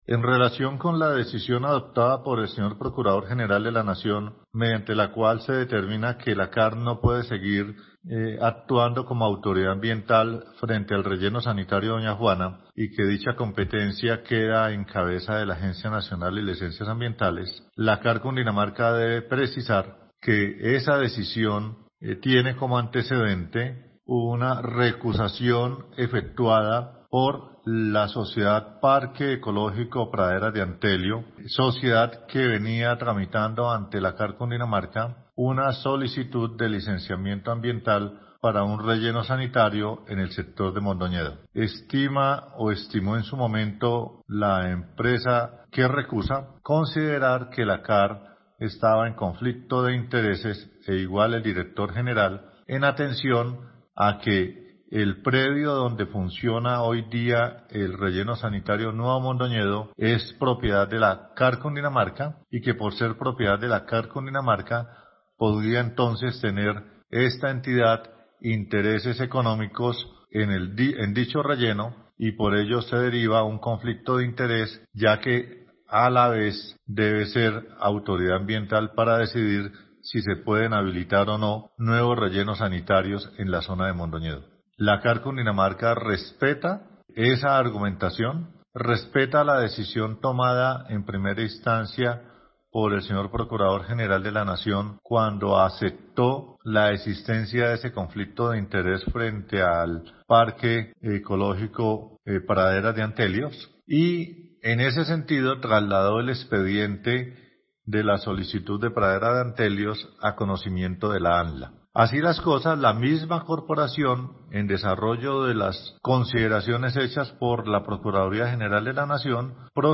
Declaraciones del director general de la CAR Cundinamarca Néstor Franco, sobre su competencia con el manejo de los rellenos sanitarios de su jurisdicción: